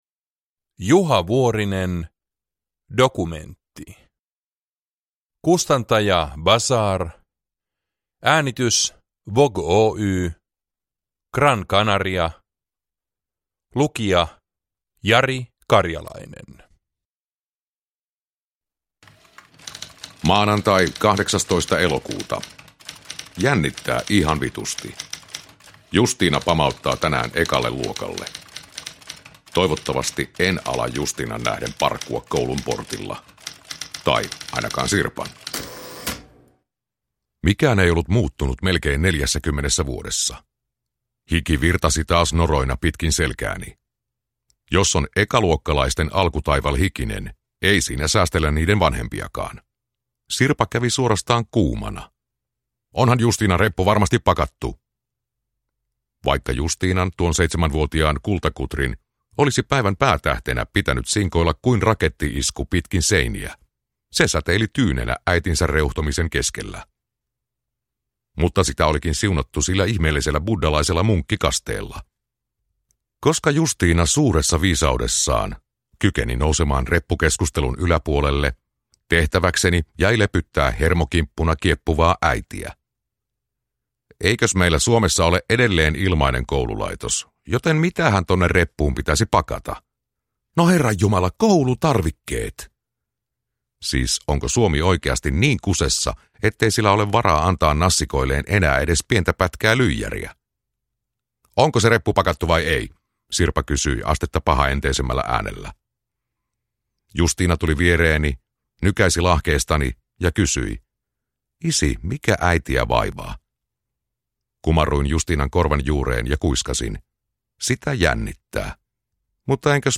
Dokumentti – Ljudbok